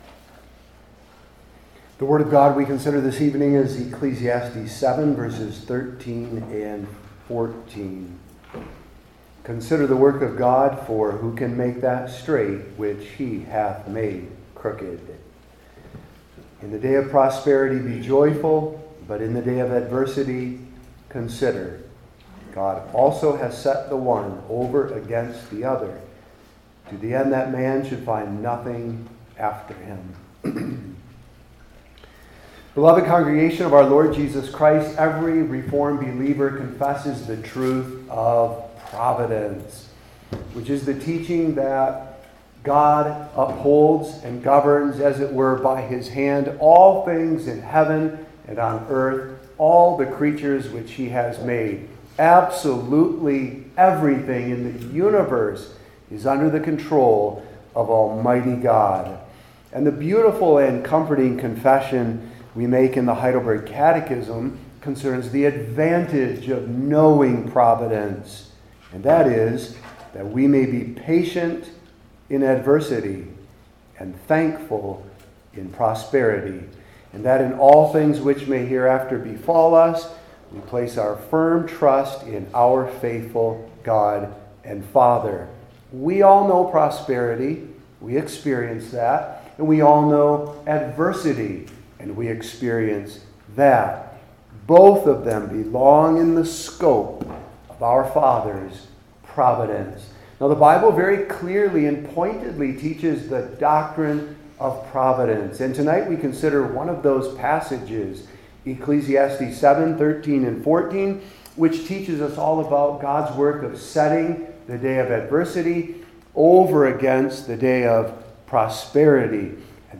Old Testament Individual Sermons I. The Work of God II.